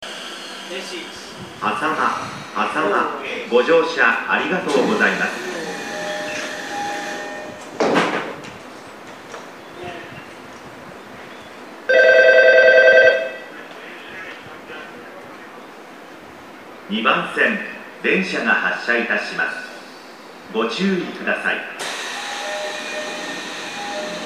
駅放送
到着発車 -- ベルのみで発車放送が流れないことがあります。